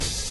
Open Hat (Whats Good).wav